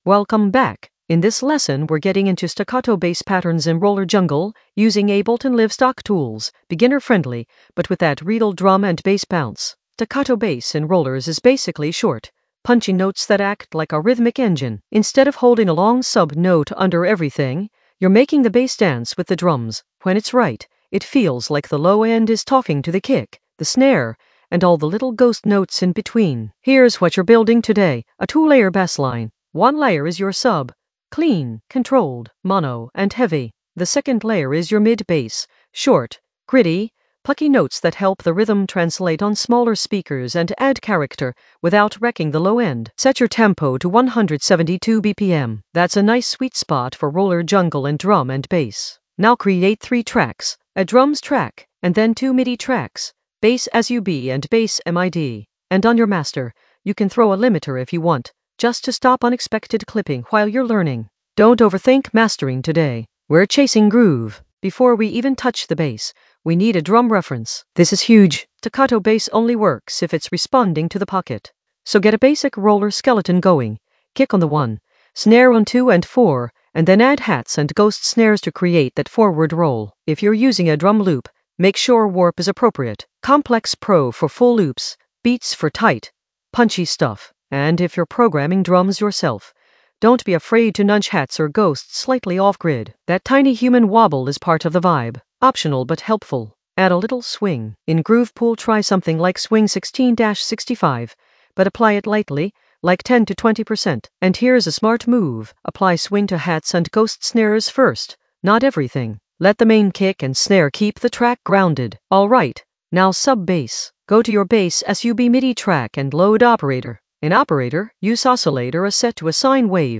Narrated lesson audio
The voice track includes the tutorial plus extra teacher commentary.
An AI-generated beginner Ableton lesson focused on Staccato bass patterns in roller jungle in the Basslines area of drum and bass production.